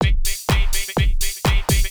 House / Loop / DRUMLOOP181_HOUSE_125_X_SC2.wav